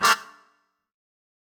GS_MuteHorn-Gmin9.wav